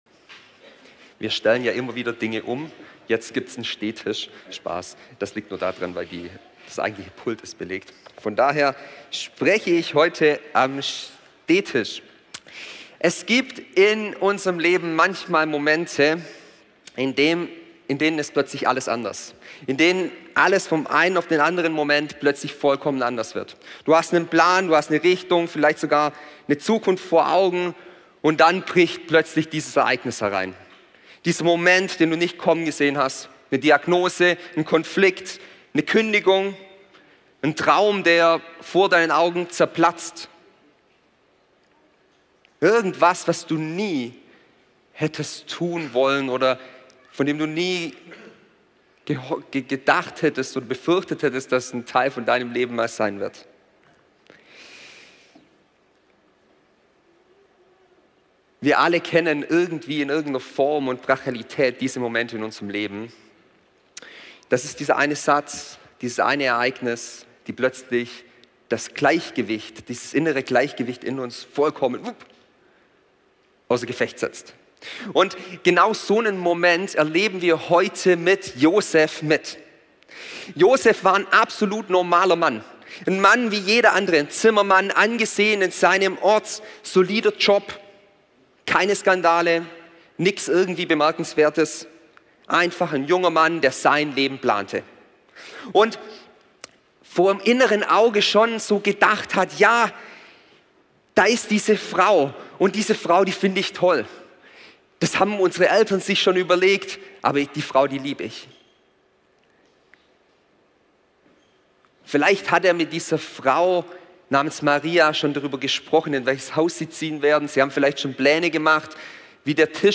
Advent | Josef – wenn Gnade Mut verlangt Serie: Advent Passage: Matthäus 1, 18-25 Typ: Predigt In der Weihnachtsgeschichte steht Josef oft im Schatten.